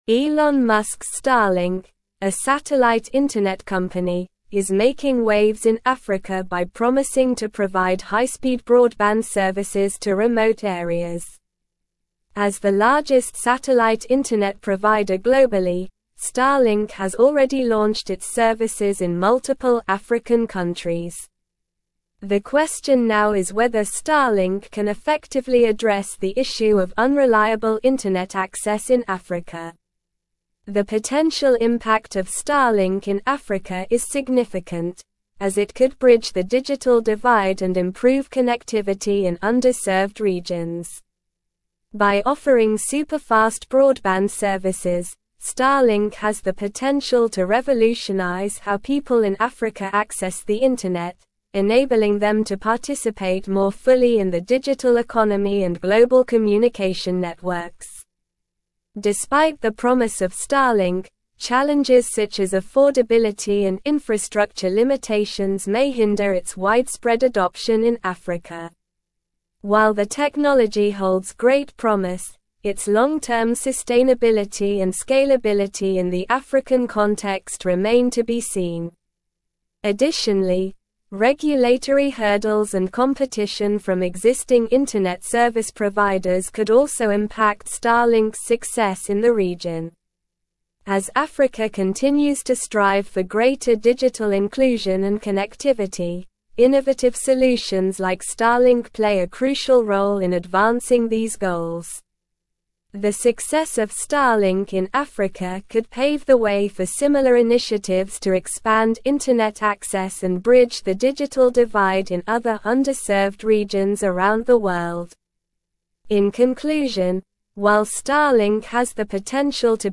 Slow
English-Newsroom-Advanced-SLOW-Reading-Starlink-Revolutionizing-African-Connectivity-with-Satellite-Internet.mp3